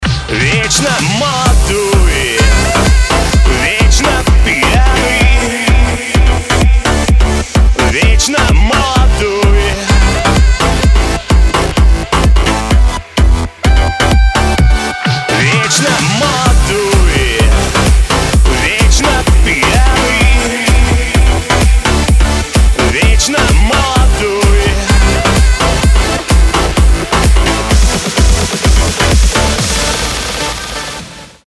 dance
house